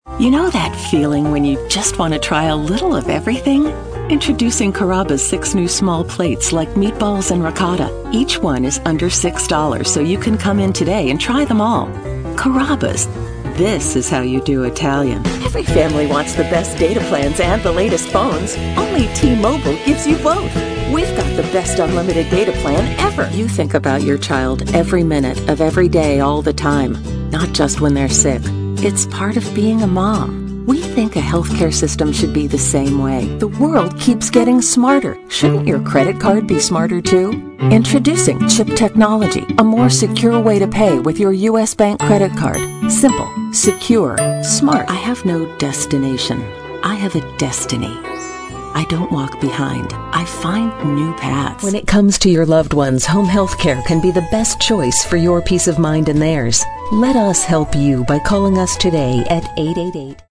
Clear, unaccented North American English voiceover.
Voice acting is conversational and natural, putting listeners at their ease while keeping their interest.
Sprechprobe: Werbung (Muttersprache):
confident, informative, knowledgeable, real sounding, conversational